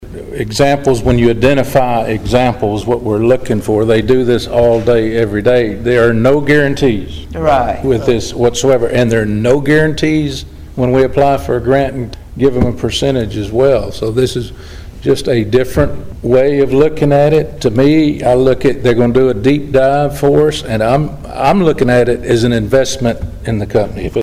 Mayor Mansfield Had these comments:
mayor-mansfield-city-council-meeting-june-25.mp3